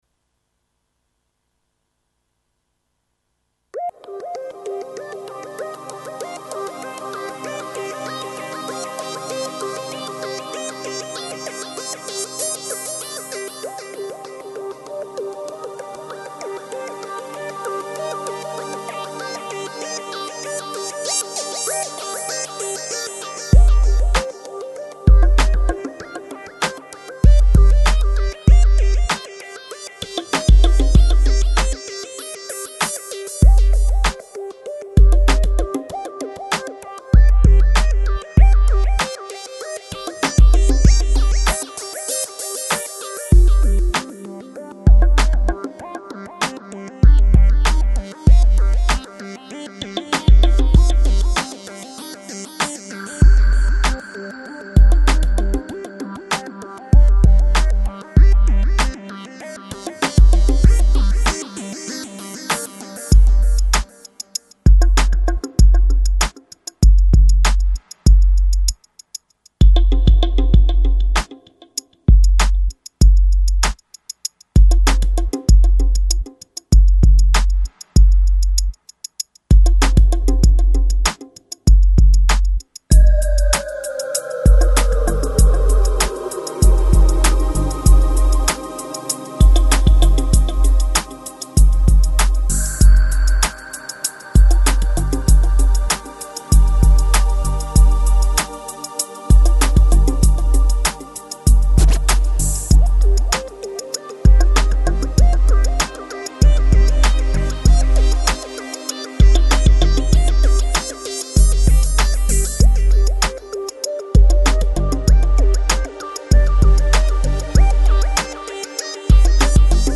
Жанр: Downtempo, Lounge